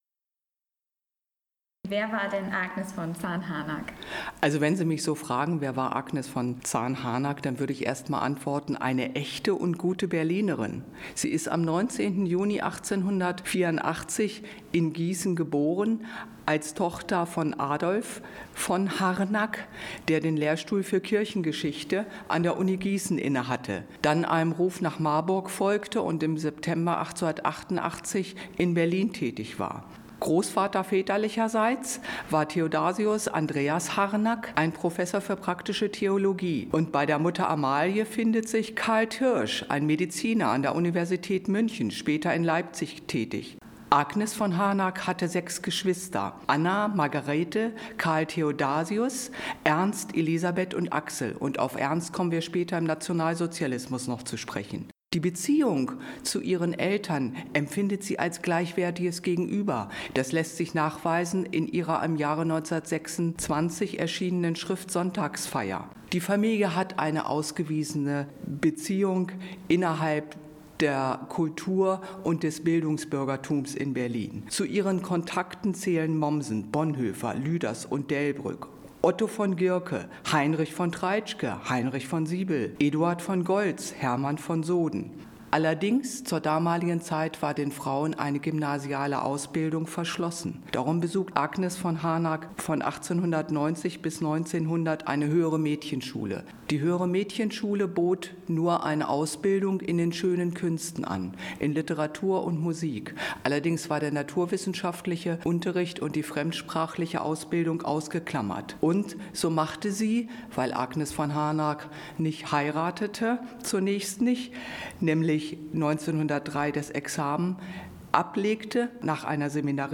April 2016: FEATURE CAMPUS Radio Berlin Greetings Peter Muller-Munk Exhibition 21th November 2015 Pittsburgh U.S.A. MEHR 20.